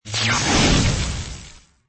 skill_shandian.mp3